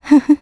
Isolet-Vox_Happy1_jp.wav